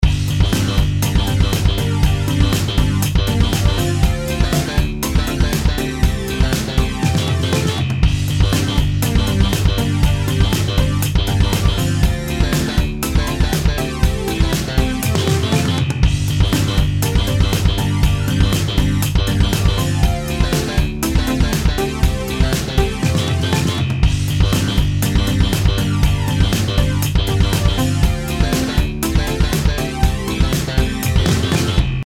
音楽ジャンル： ロック
LOOP推奨： LOOP推奨
楽曲の曲調： HARD